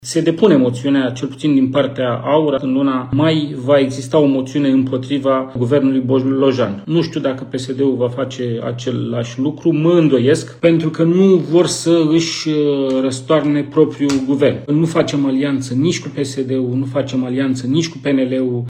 Într-un live pe pagina sa de Facebook în care a anunțat depunerea moțiunii de cenzură, liderul partidului AUR, George Simion a precizat că nu va face alianță nici cu PSD, nici cu PNL.